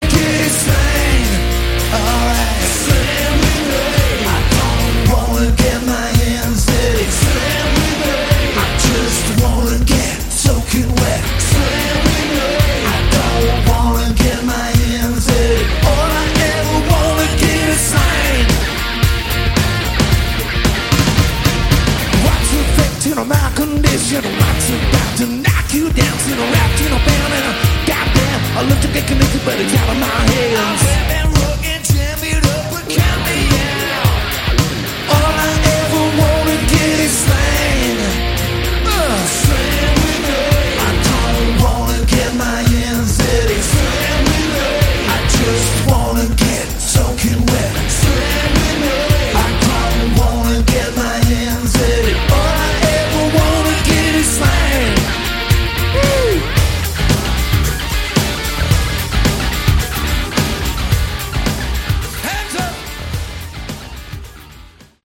Category: Hard Rock
Recorded on their Las Vegas residency at 'The Joint' in 2013